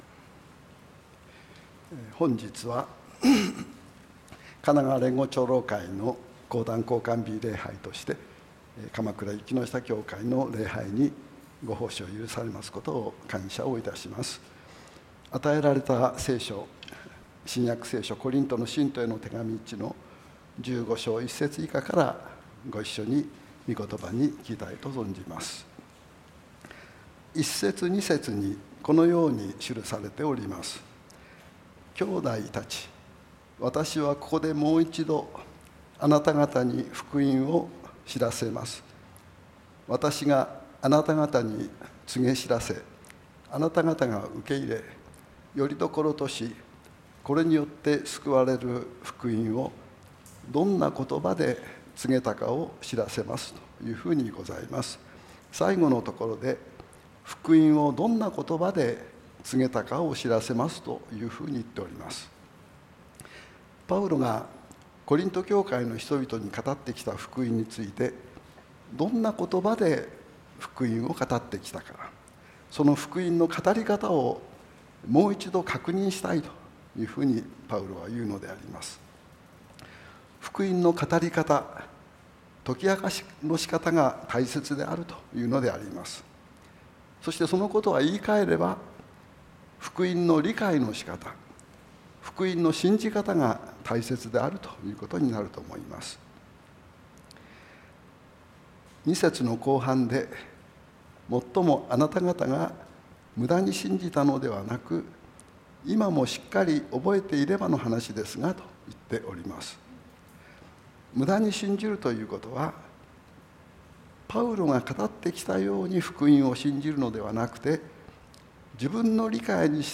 主日礼拝
本日は、神奈川連合長老会の講壇交換日礼拝として、鎌倉雪ノ下教会の礼拝にご奉仕を許されますことを感謝いたします。 与えられた聖書、新約聖書コリントの信徒への手紙一１５章１節以下から、ご一緒に、御言葉に聞きたいと存じます。